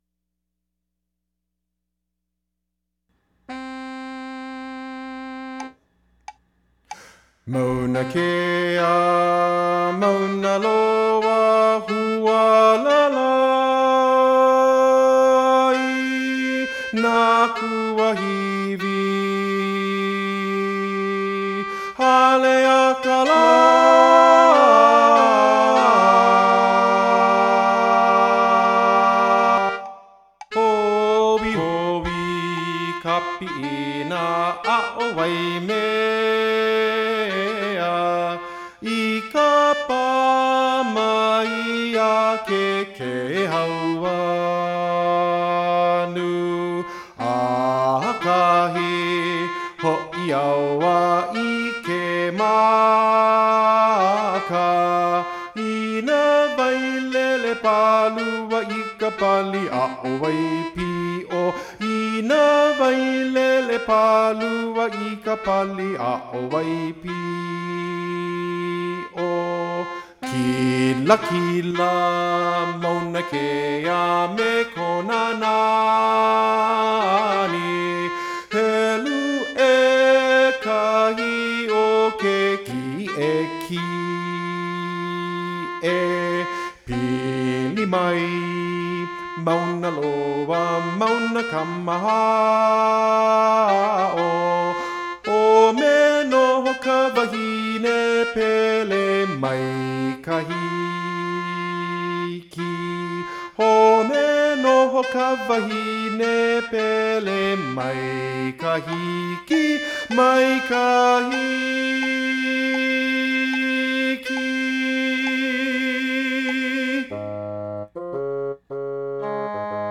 Soprano   Instrumental | Downloadable